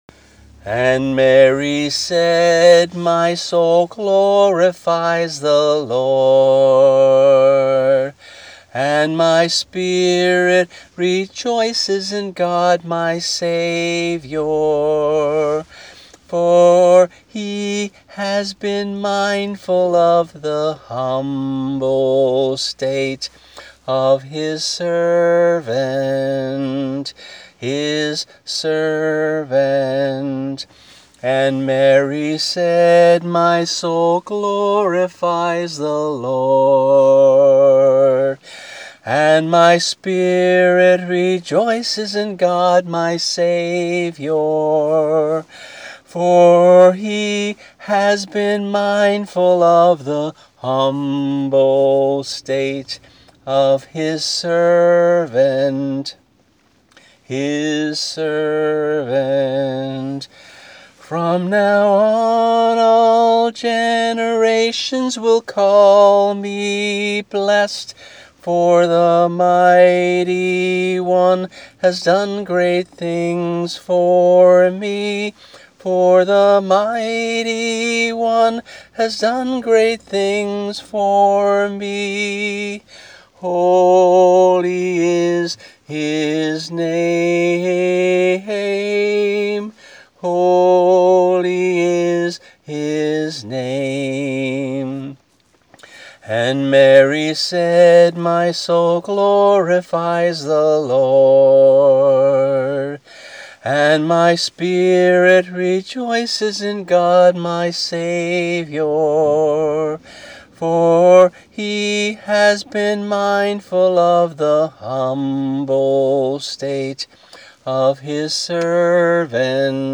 MP3 - voice only